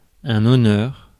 Ääntäminen
GenAm: IPA : /ˈɑ.nɚ/ US : IPA : [ˈɑ.nɚ]